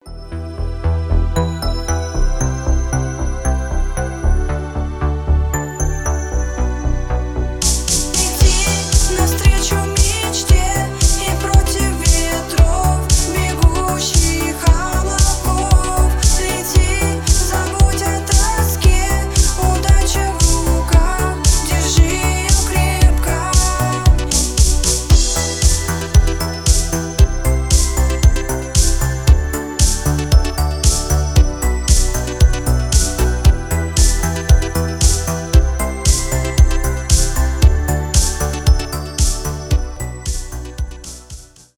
synth pop , поп , мелодичные